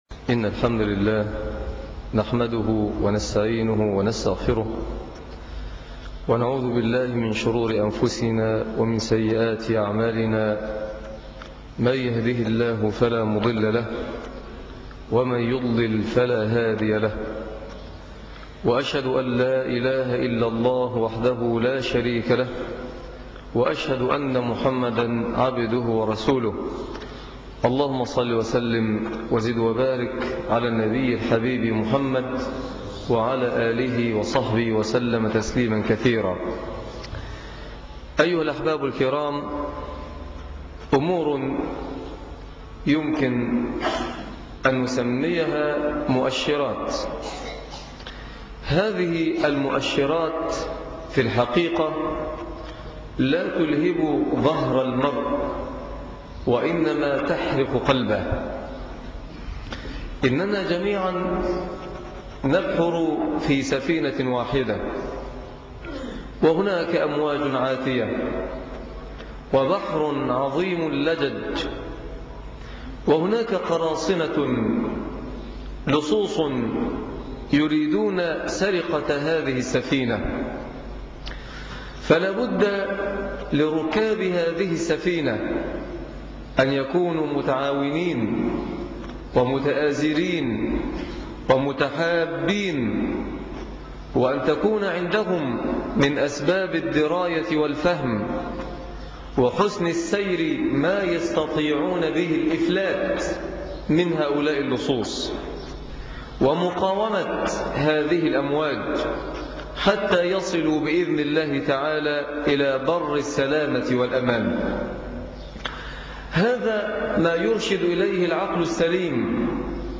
اعرف عدوك - خطب الجمعة